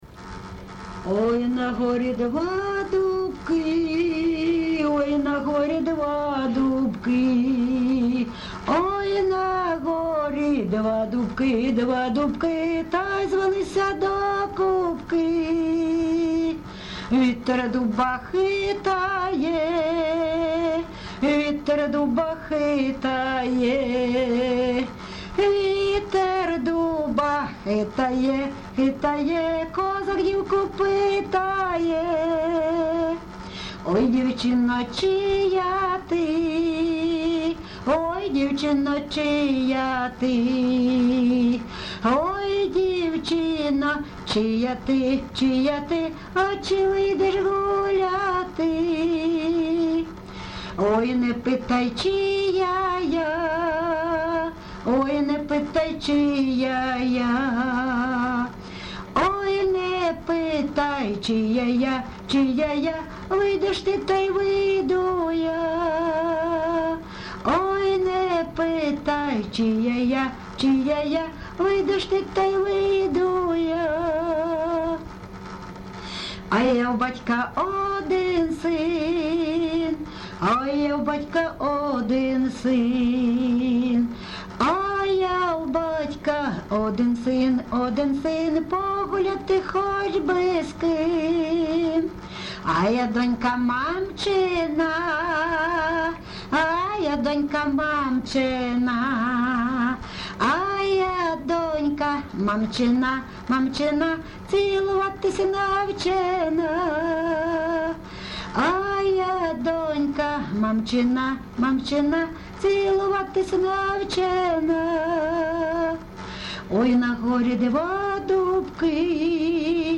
ЖанрПісні з особистого та родинного життя, Пісні літературного походження
Місце записус. Лозовівка, Старобільський район, Луганська обл., Україна, Слобожанщина